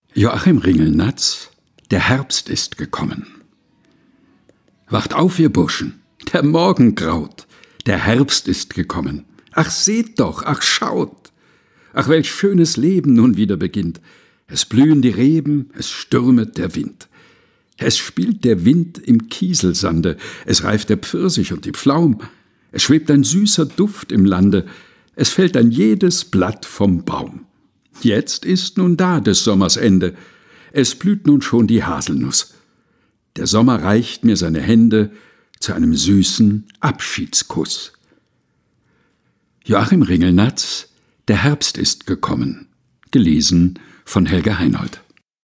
Texte zum Mutmachen und Nachdenken - vorgelesen